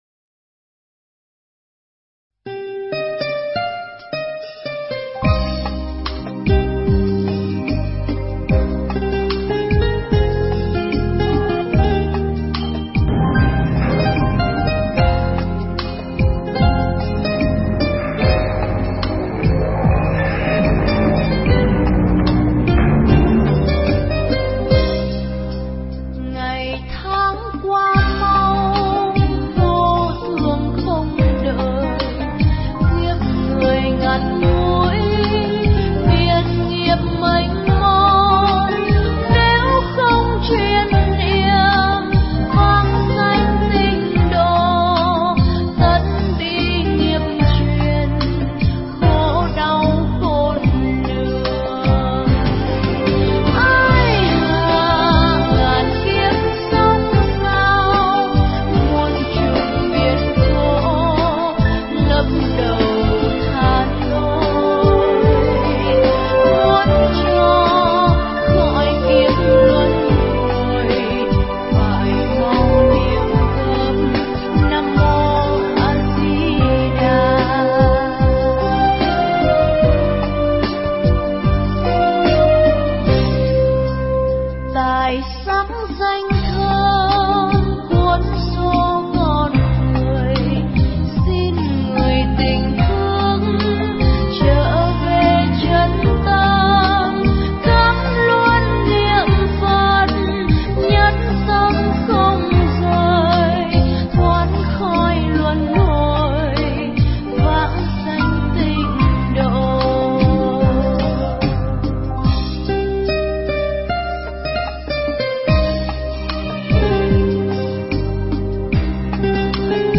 Pháp thoại
giảng cho GĐ Tịnh Độ Liên Hương tại chùa Quảng Tế (Huế)